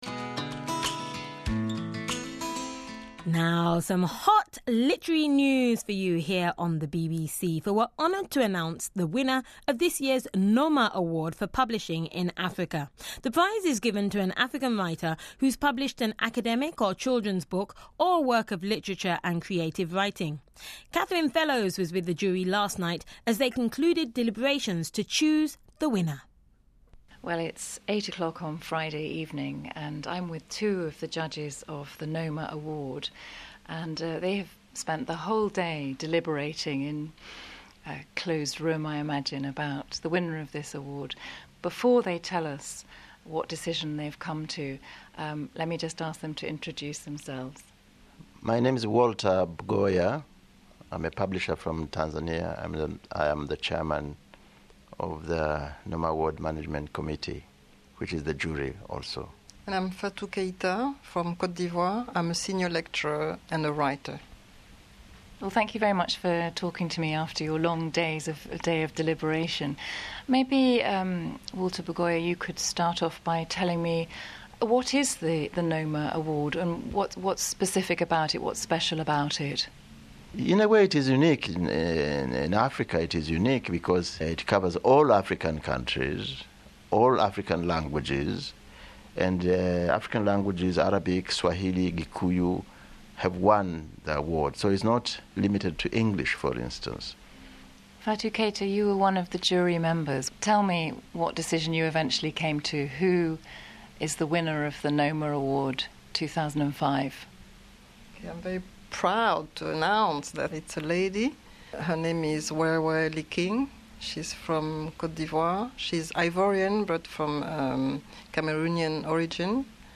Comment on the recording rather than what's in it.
The following interviews were broadcast on BBC Network Africa at the Weekend on BBC World Service to audiences across the African continent between October 2005 and March 2006.